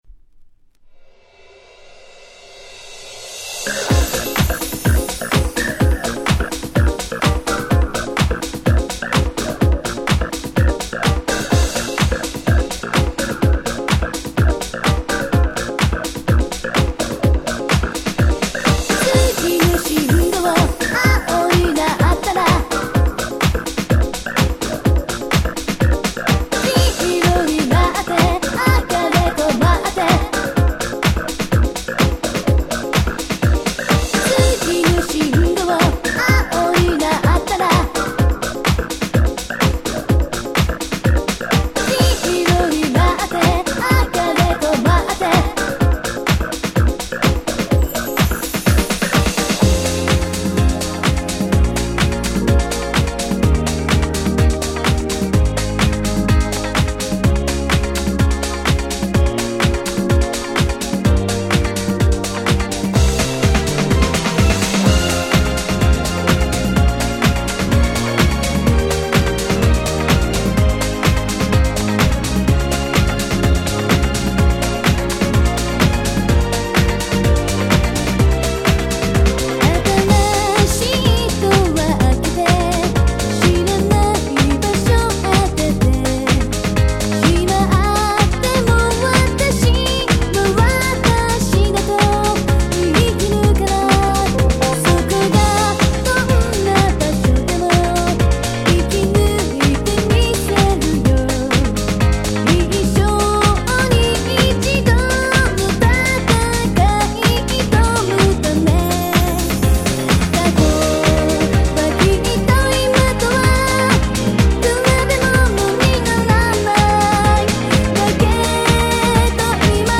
Nice Japanese House !!!!!